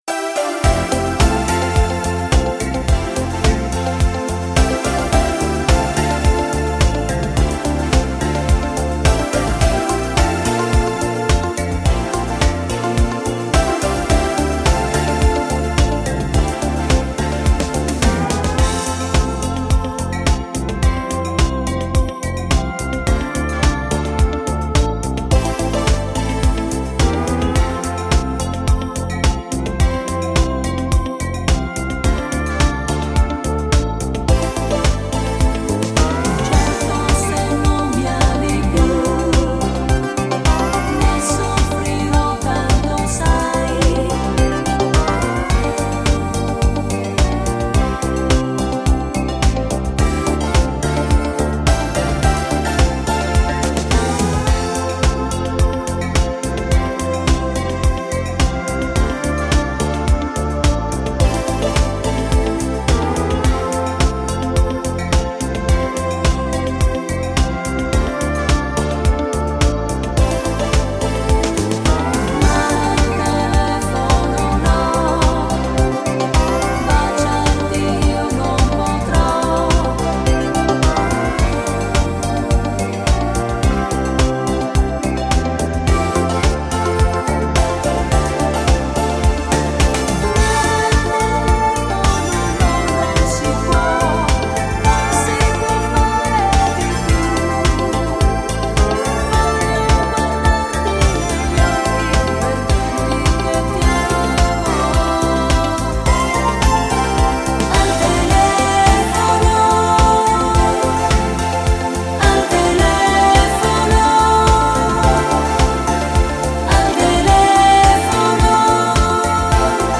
Genere: Moderato disco